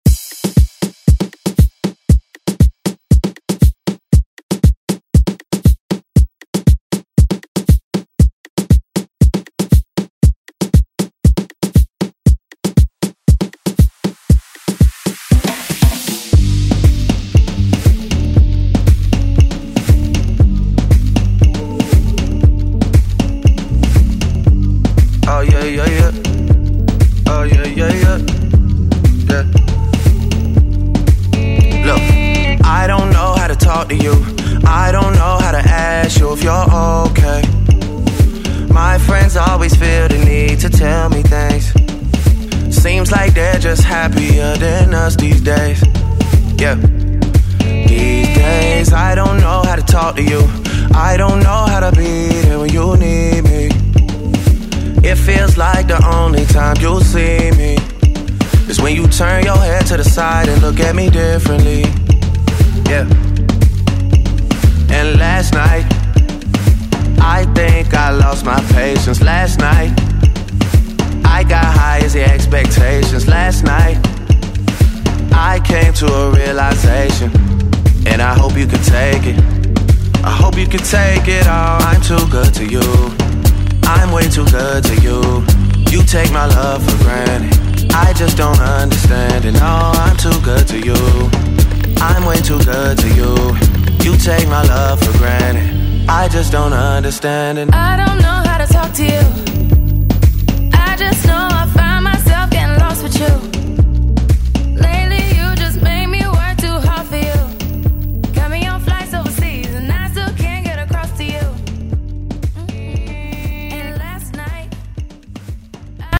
Electronic Pop Rock Music
Genres: 2000's , RE-DRUM , ROCK
Clean BPM: 98 Time